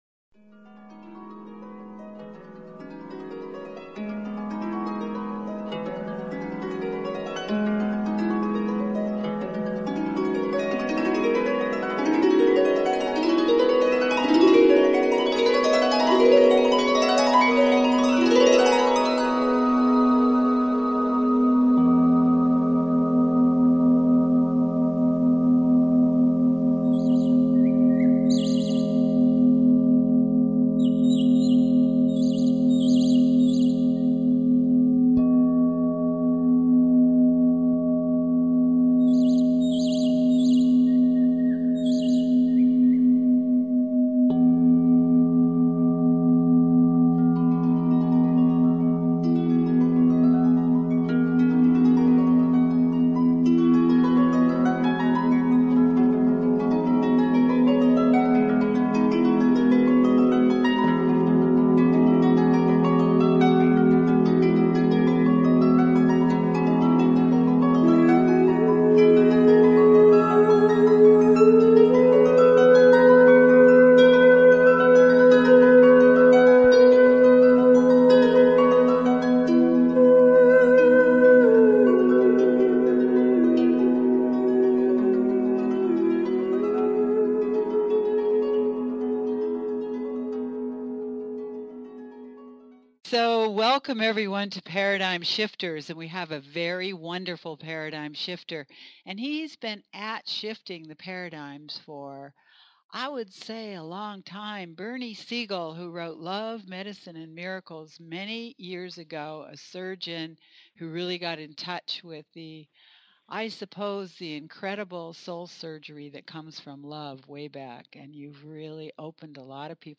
with guest Bernie S Segal